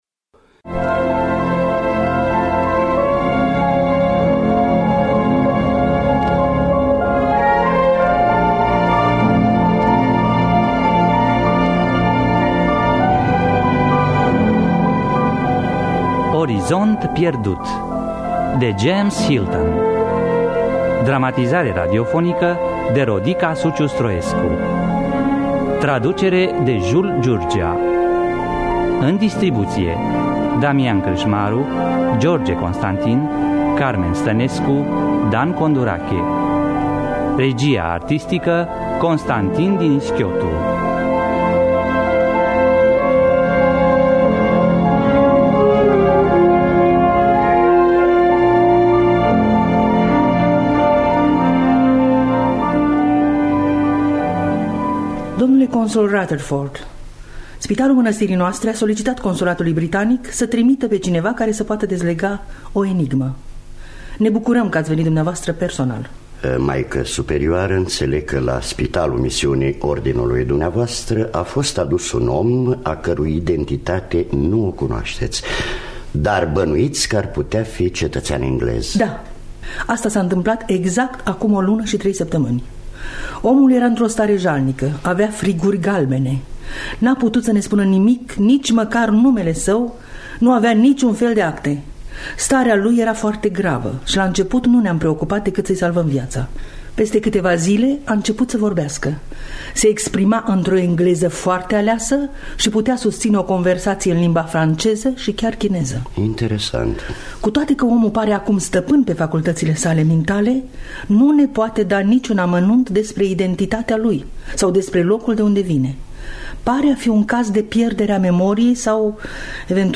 Orizont pierdut de James Hilton – Teatru Radiofonic Online